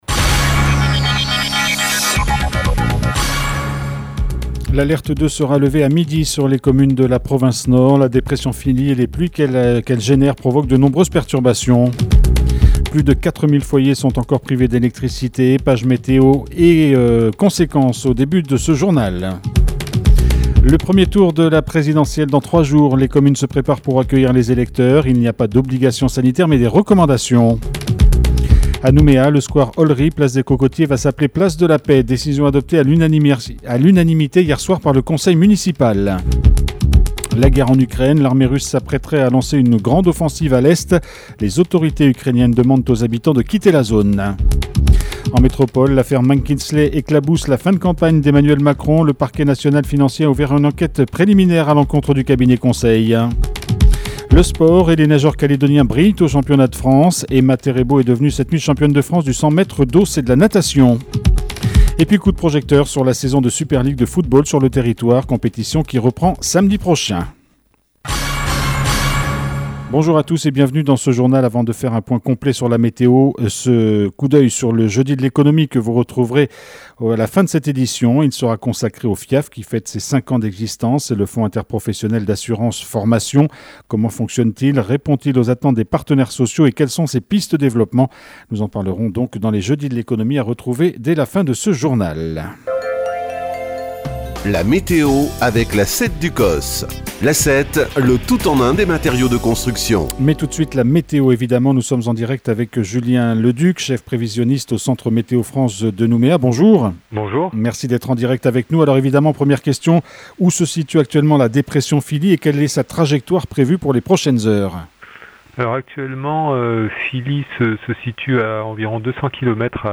JOURNAL : JEUDI 07/04/22 (MIDI)